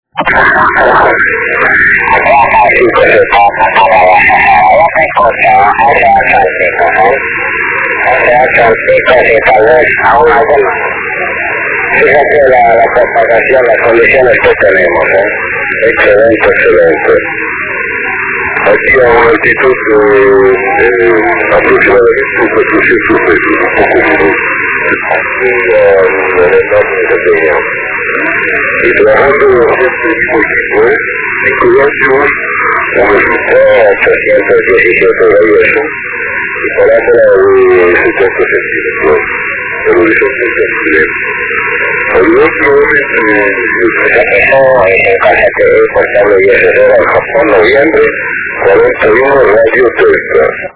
Archivos sonido de QSOs en 10 GHz SSB
613 Kms Tropo Mar